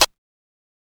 kits/OZ/Closed Hats/OZ-Hihat 12.wav at main
OZ-Hihat 12.wav